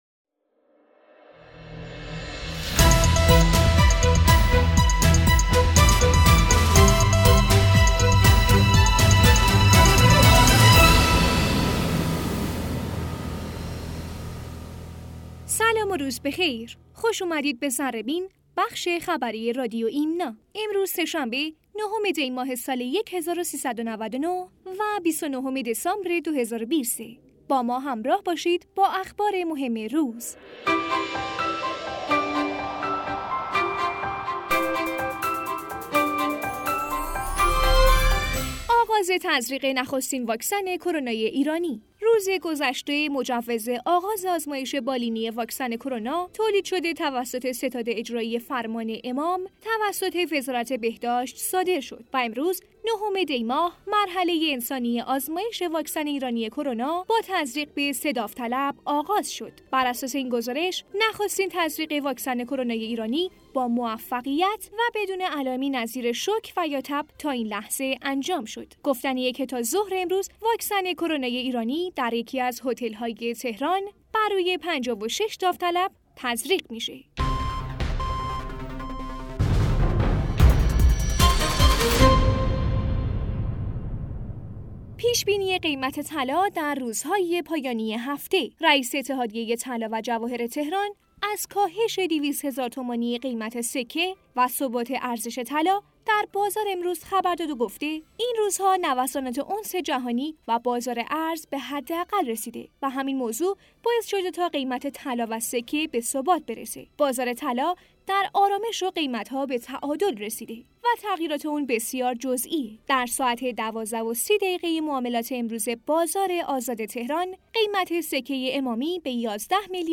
گوینده: